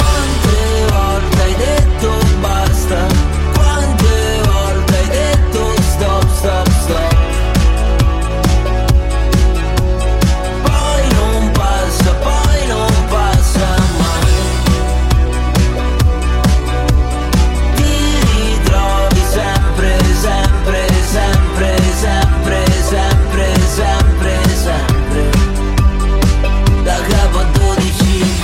Genere: pop,disco,trap,rap,dance,hit